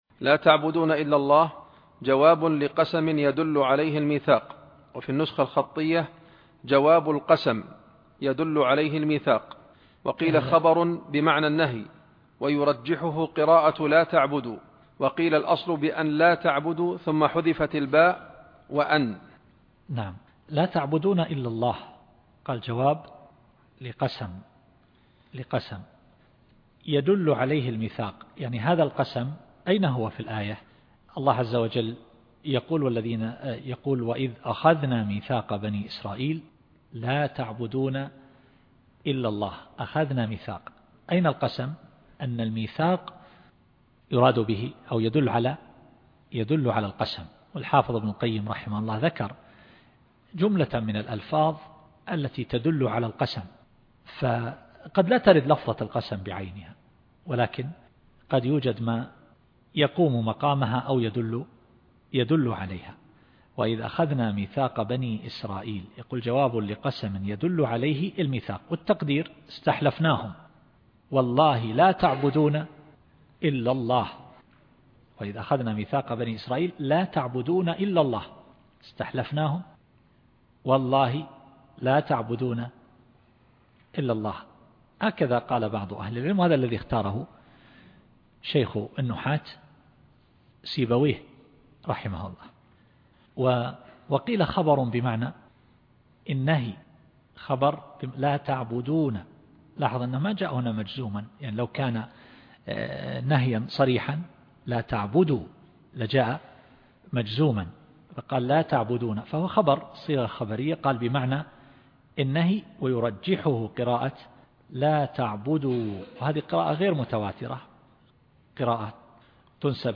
التفسير الصوتي [البقرة / 83]